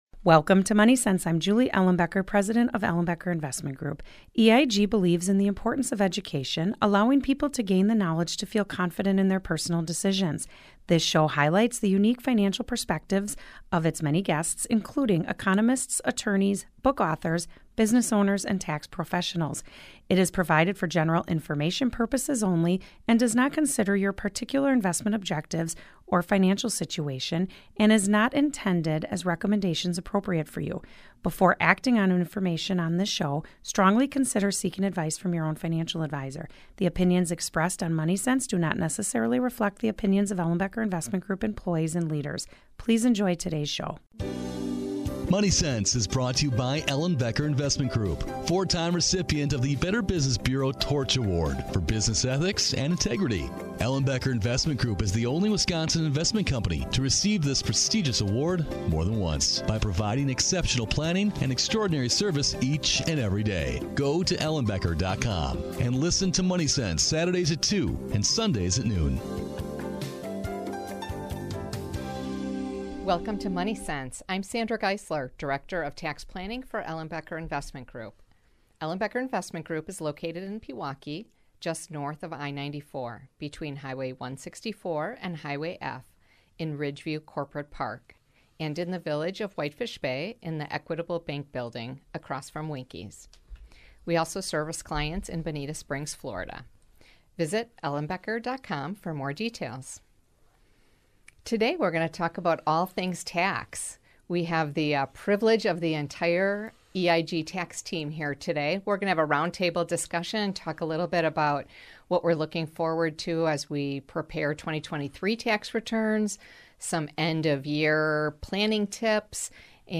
EIG Tax Team Roundtable
Join the EIG Tax Team for an end-of-year tax discussion covering the new residential energy credits, electric vehicle credits, and Secure Act 2.0 changes to retirement savings accounts. They highlight end-of-year tax planning tips plus tax issues they plan to watch closely in 2024.